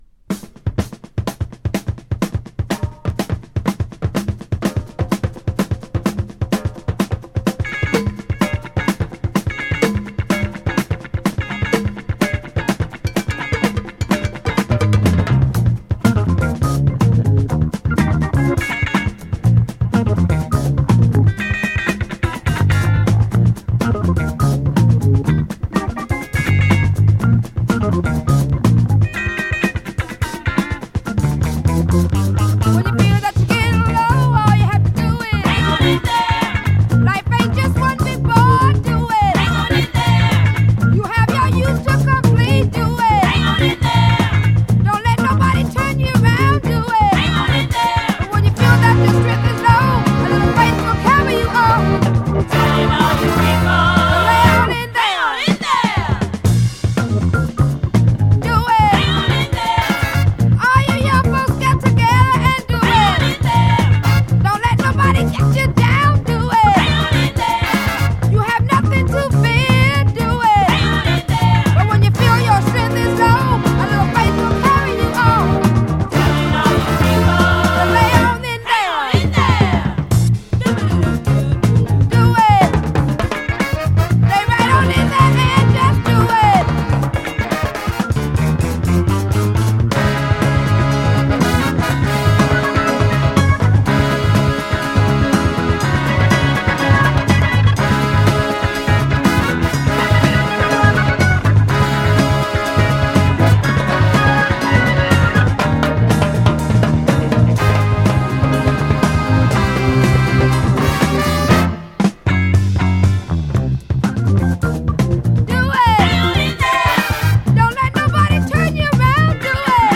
SOUL
FUNKY LADY SOUL〜RARE GROOVE CLASS… 続き